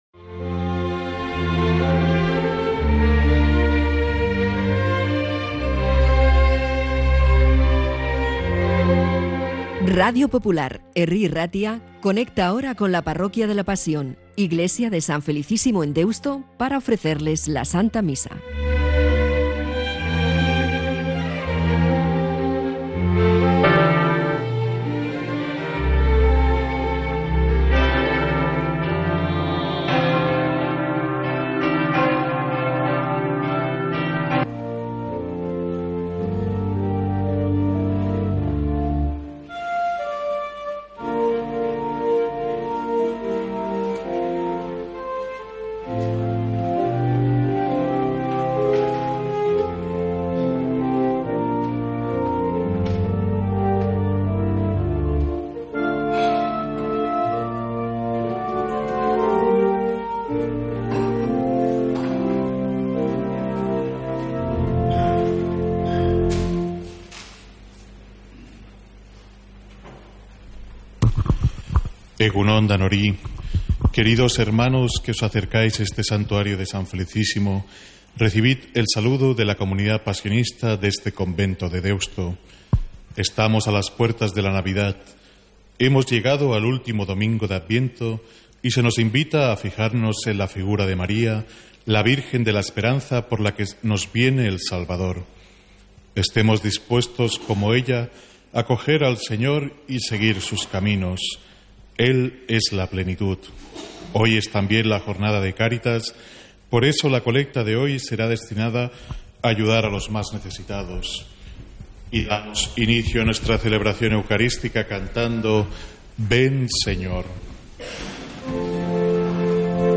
Santa Misa desde San Felicísimo en Deusto, domingo 22 de diciembre